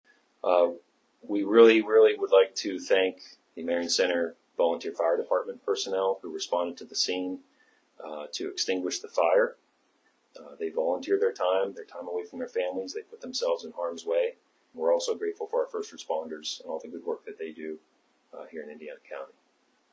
At a press conference Monday afternoon, state troopers provided more information about the officer involved shooting that happened Sunday evening in Grant Township.